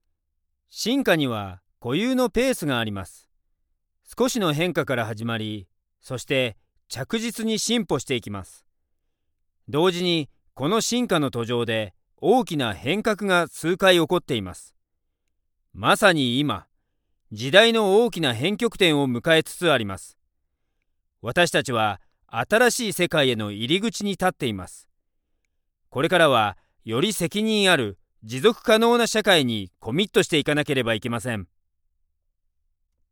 Vidéos explicatives
Rode NT1A
MOTU M2